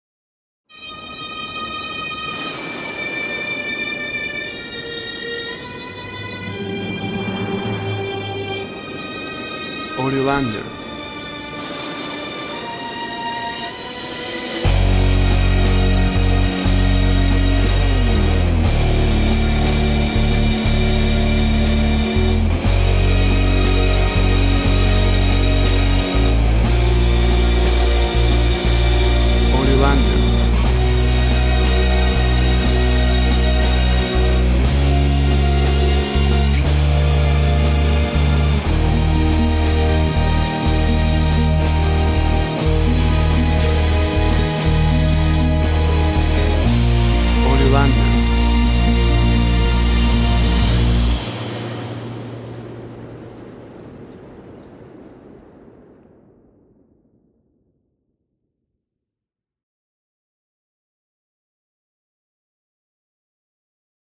Second part – music for video games – Incidental.
Tempo (BPM) 80